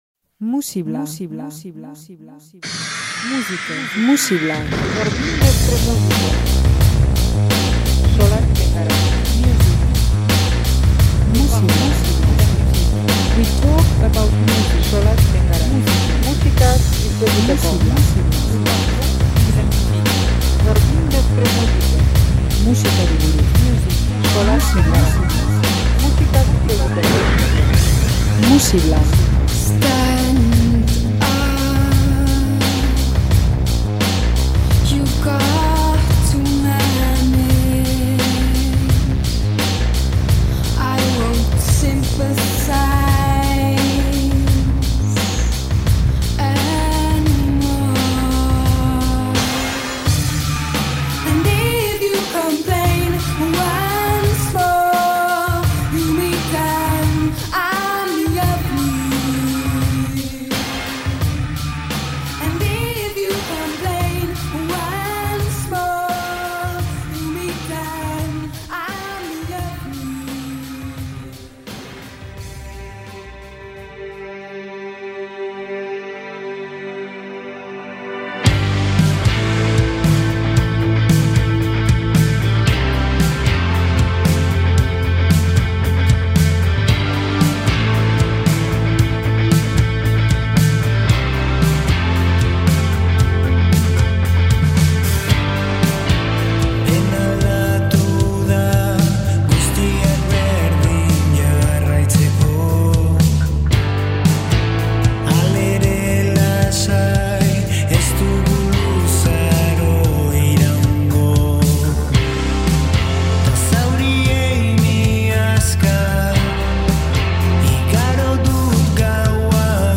rock alternatibo eta noise kutsuko laukote gipuzkoarra da